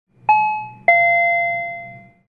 Звуки дверного звонка
Электронный звук тихого звонка в дверь